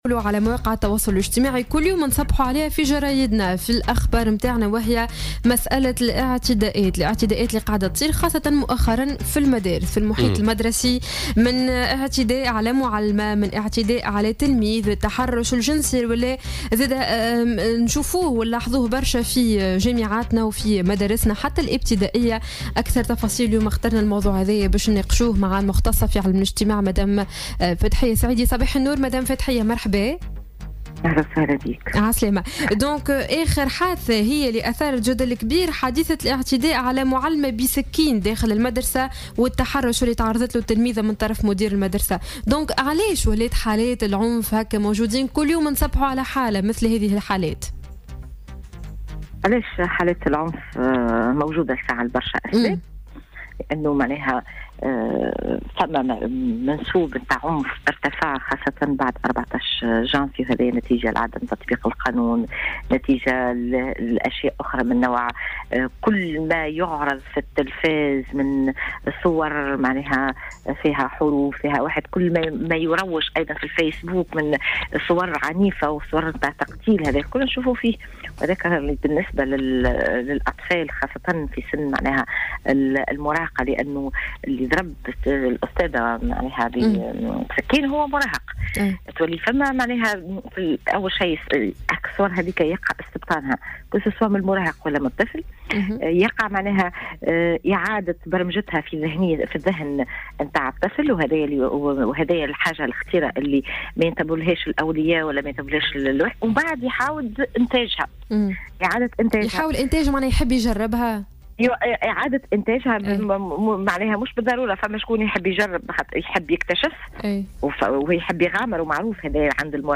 Croissance de la violence dans les milieux éducatifs : Une sociologue explique